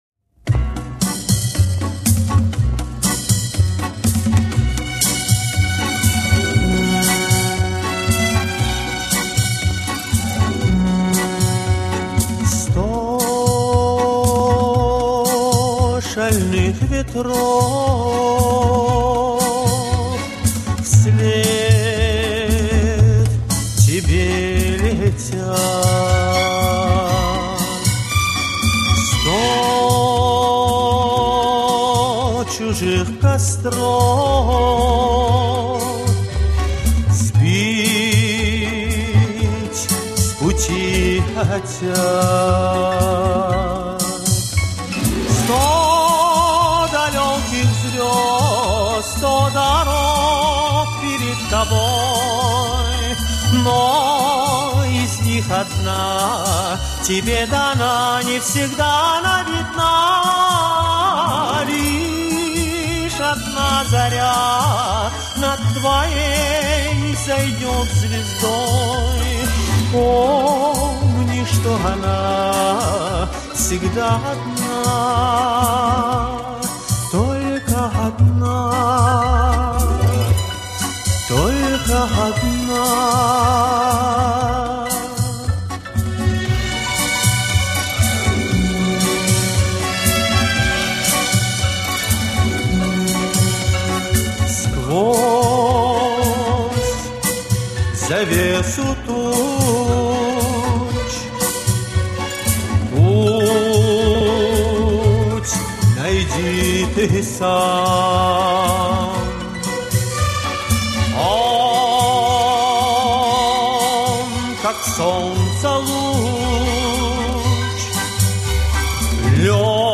Инстр. анс